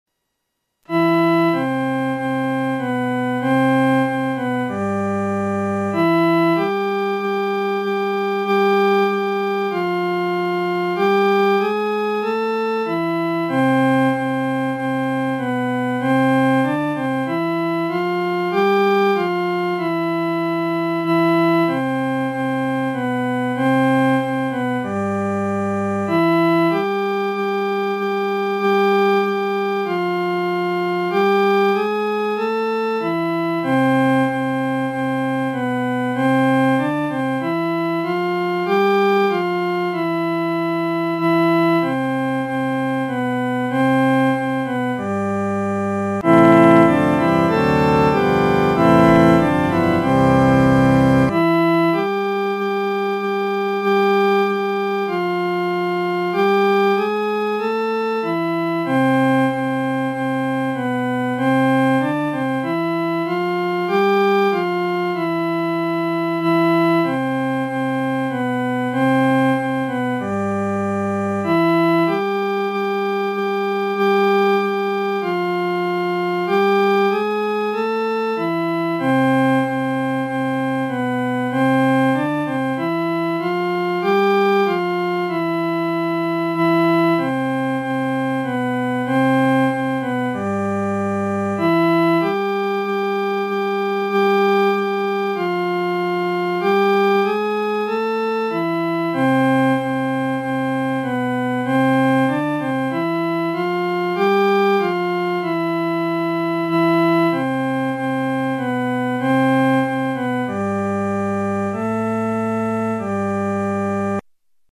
伴奏
女低
所用曲谱是在河北省一带于解放前所流行的民歌，曾被配上多种歌词。